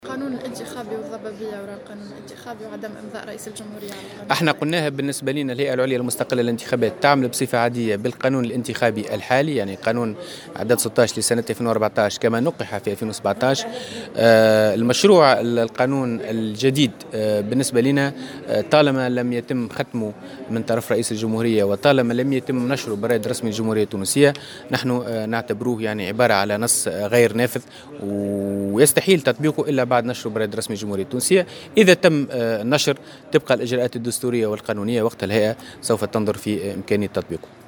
أكد نائب رئيس الهيئة العليا المستقلة للانتخابات، فاروق بوعسكر، في تصريح لموفدة "الجوهرة أف أم" اليوم الأربعاء، أن الهيئة تعمل بالقانون الانتخابي الحالي عدد 16لـ 2014 وكما تم تنقيحه في 2017. وتابع على هامش لقاء نظمته الهيئة العليا المستقلة للانتخابات بسوسة، ان مشروع القانون الجديد يعتبر غير نافذ طالما أنه لم يُختم من طرف رئيس الجمهورية ولم ينشر بالرائد الرسمي. وأكد انه في حال نشره طبقا للإجراءات القانونية والدستورية فإن الهيئة ستنظر وقتها في إمكانية تطبيقه، وفق تعبيره.